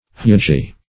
hugy - definition of hugy - synonyms, pronunciation, spelling from Free Dictionary Search Result for " hugy" : The Collaborative International Dictionary of English v.0.48: Hugy \Hu"gy\, a. Vast.